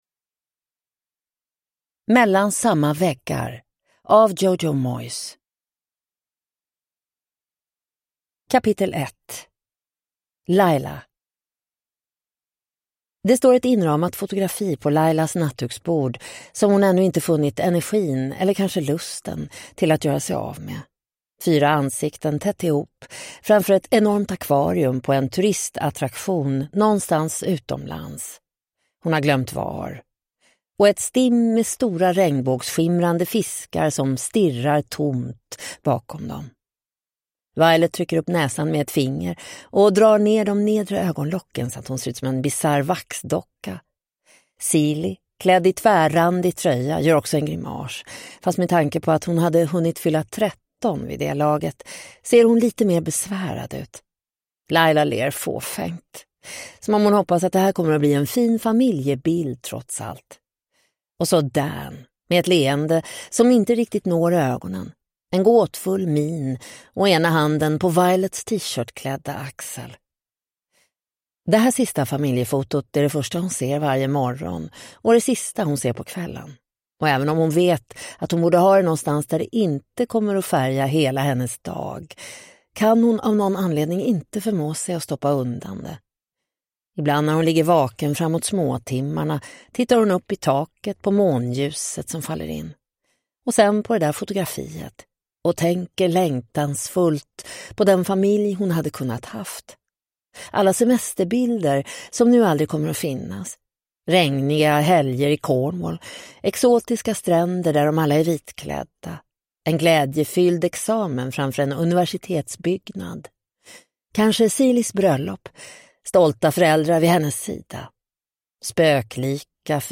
Mellan samma väggar (ljudbok) av Jojo Moyes